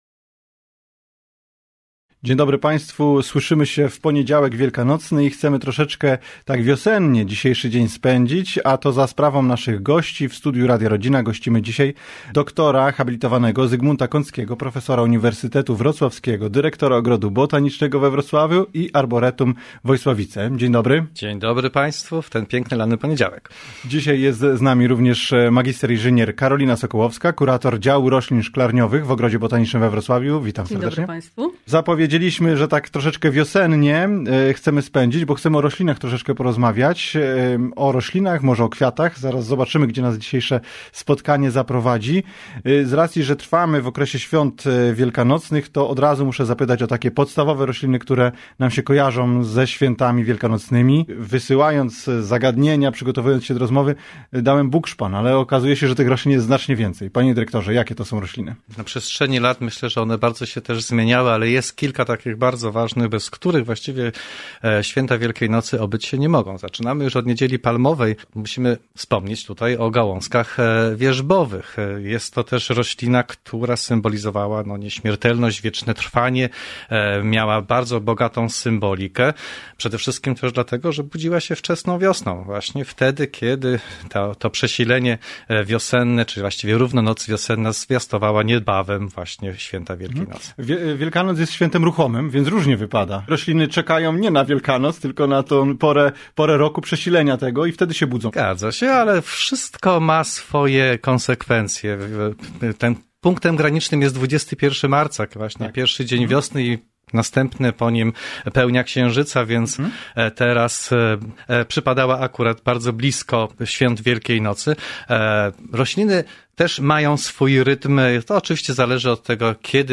Te i wiele innych pytań zadaliśmy gościom Radia Rodzina w Poniedziałek Wielkanocny.